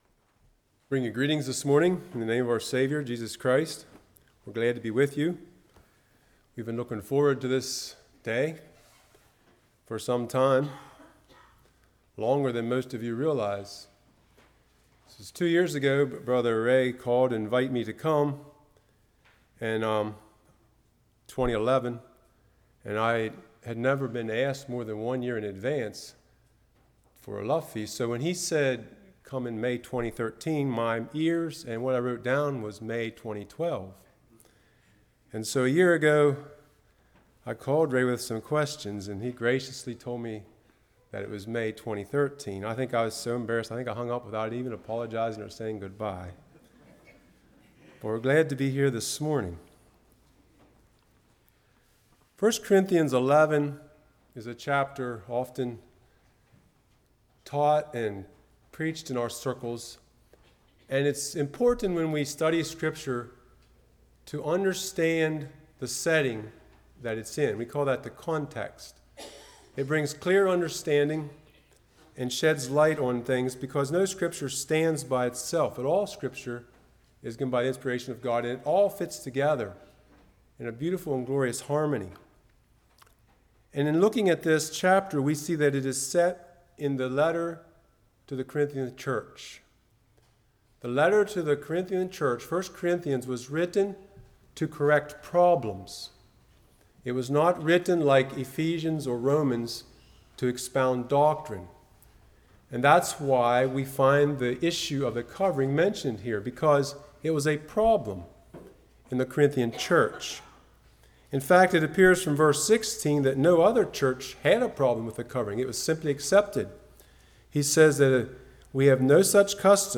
Series: Spring Lovefeast 2013 Passage: 1 Corinthians 11:1-34 Service Type: Morning